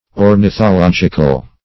\Or`ni*tho*log"ic*al\, a. [Cf. F. ornithologique.]